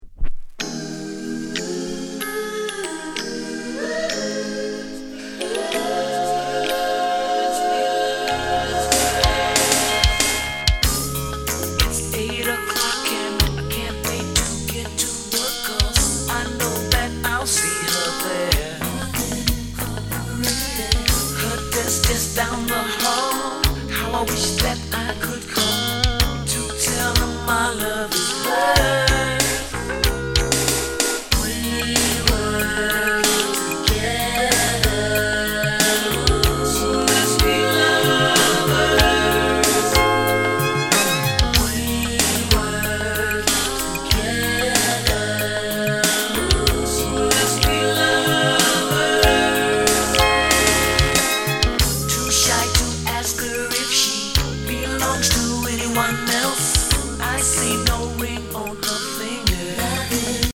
Genre: Funk